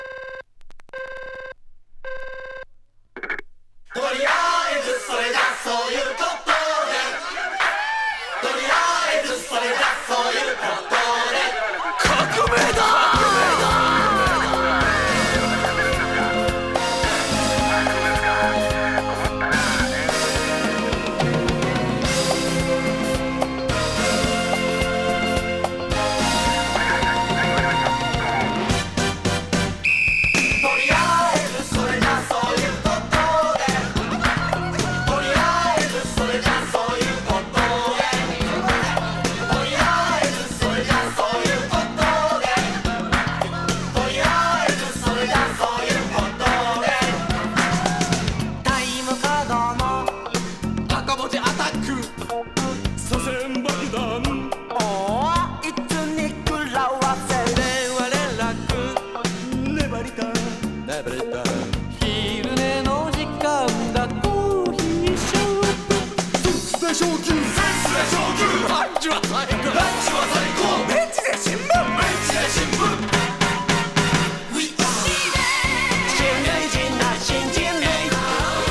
カップリングはインスト。